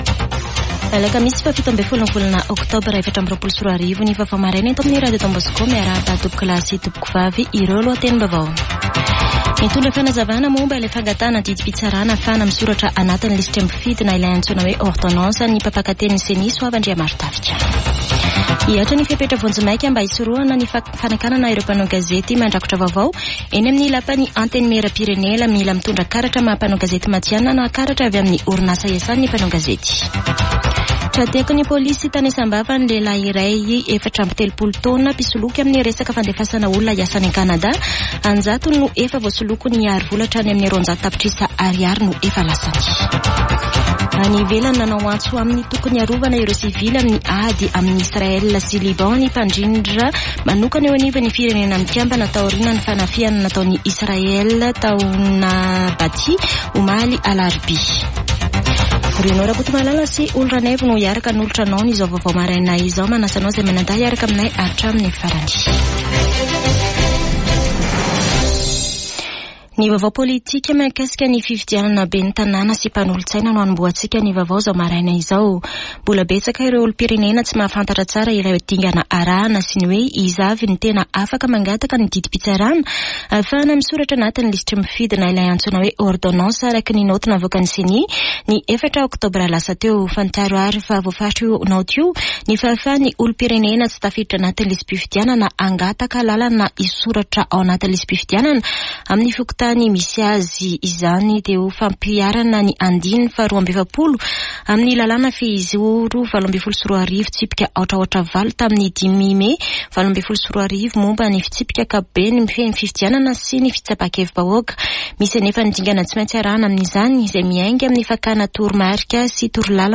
[Vaovao maraina] Alakamisy 17 oktobra 2024